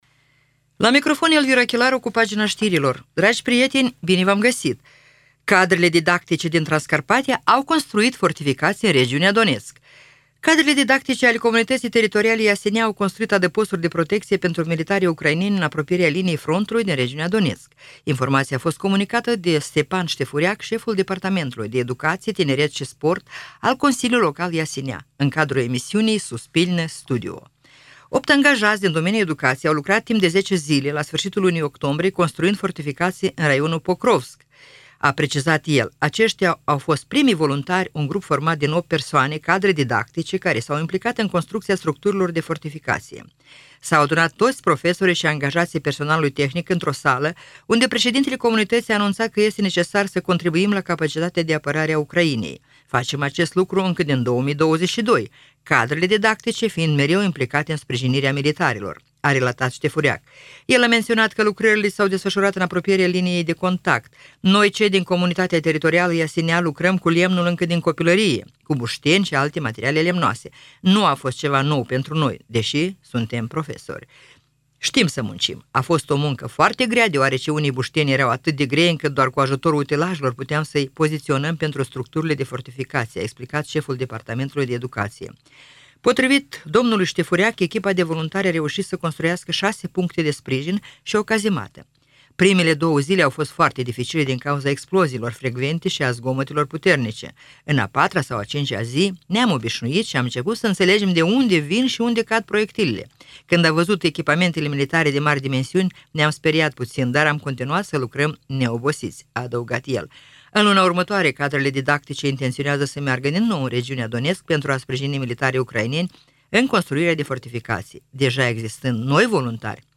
Știri Radio Ujgorod – 19.11.2024